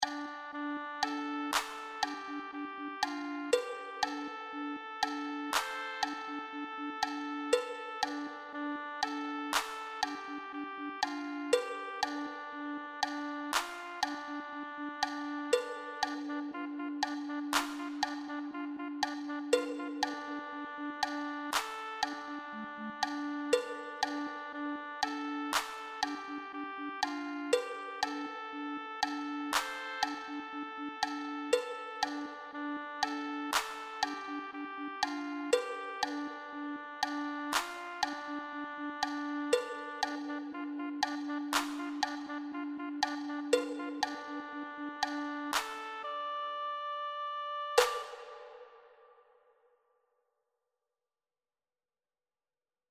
“Melodía con aire árabe para cantar- tocar y bailar„
Perc.
No-hay-betun-PERC.mp3